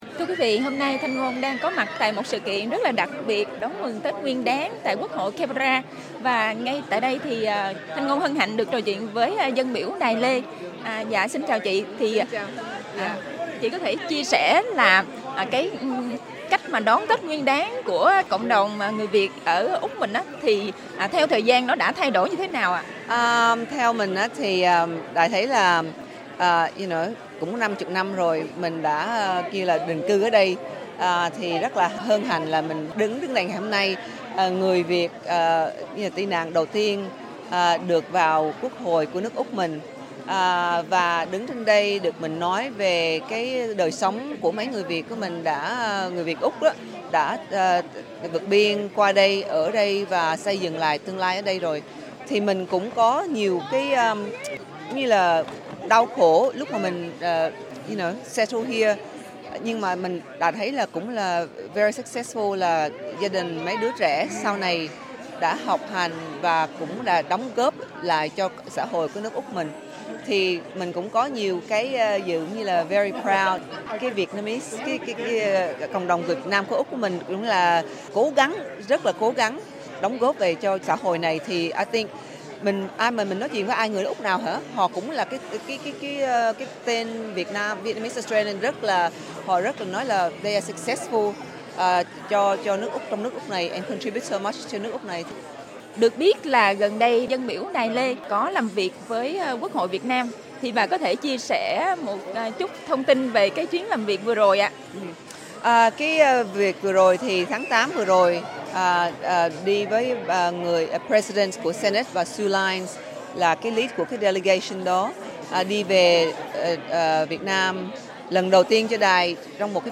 Dân biểu Đài Lê tại sự kiện mừng Tết Nguyên đán 2025 tại Toà nhà Quốc hội ở Canberra.